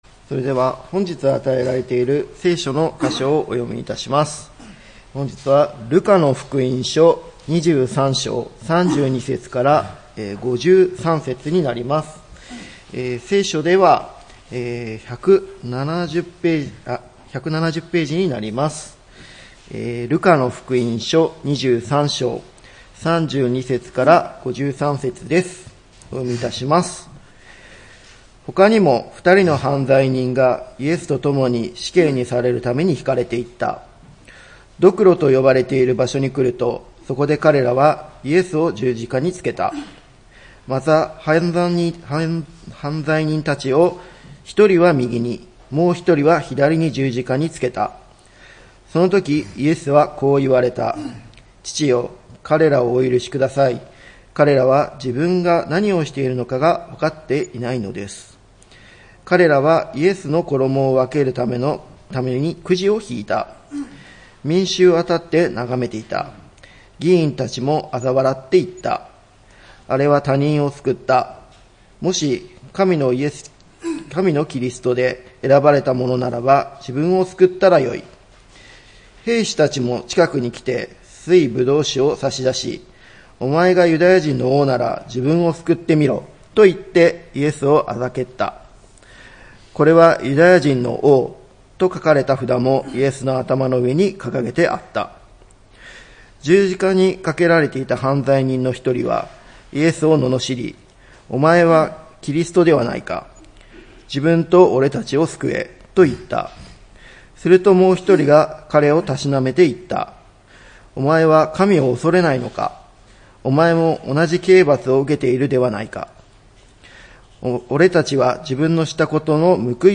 礼拝メッセージ「十字架のことば」(４月13日）